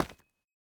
Minecraft Version Minecraft Version 21w07a Latest Release | Latest Snapshot 21w07a / assets / minecraft / sounds / block / calcite / step1.ogg Compare With Compare With Latest Release | Latest Snapshot
step1.ogg